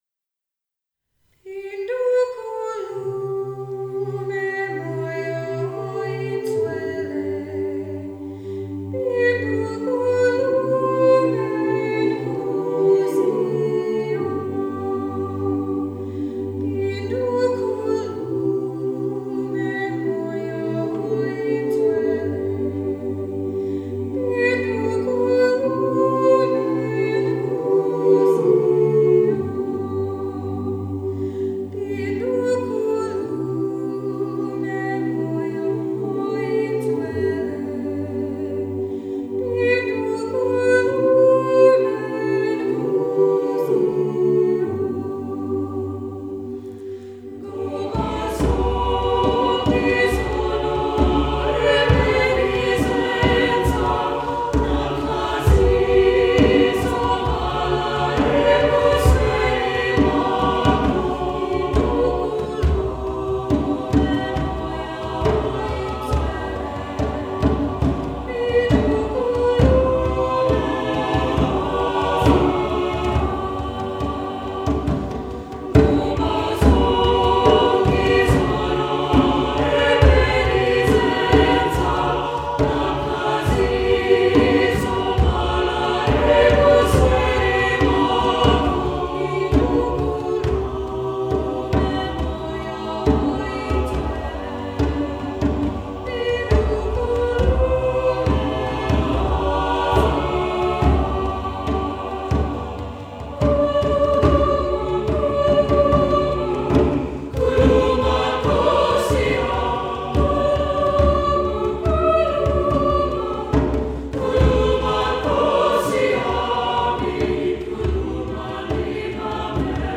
Accompaniment:      Reduction
Music Category:      Choral
Very moving, with a dramatic rise and fall.